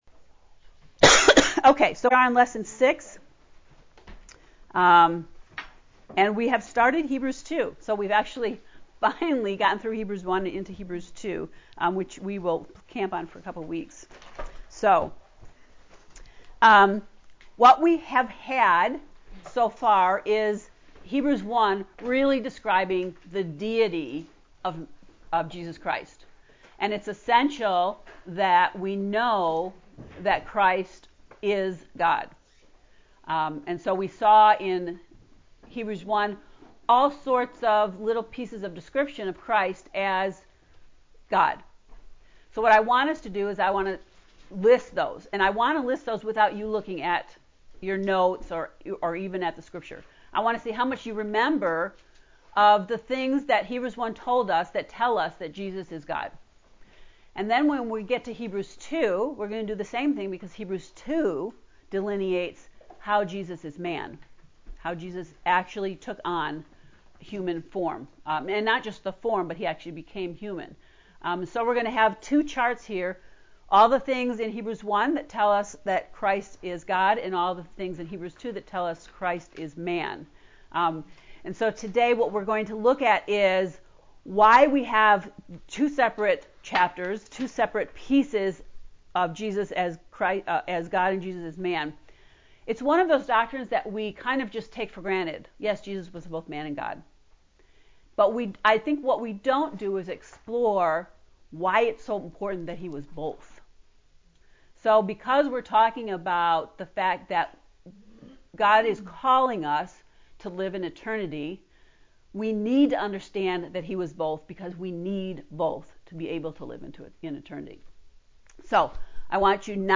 heb-lecture-6.mp3